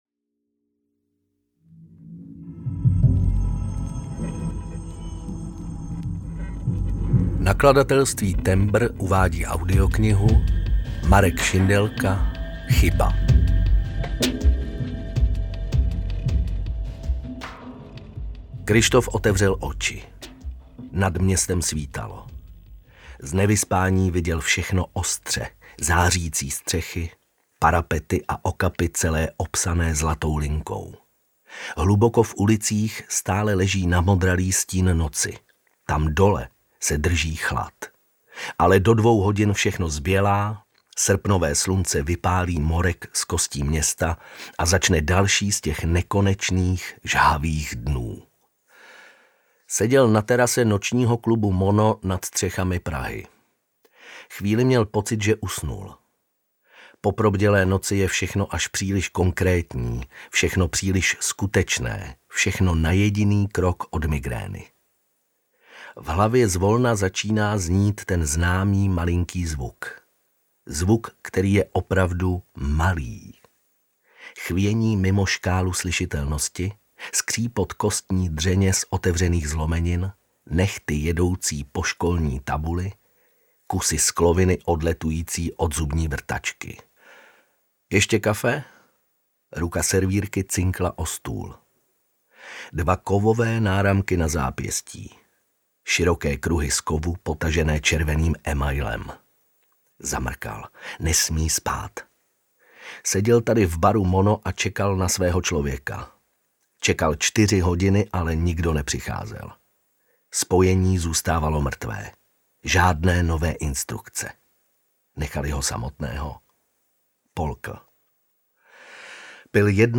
Chyba audiokniha
Ukázka z knihy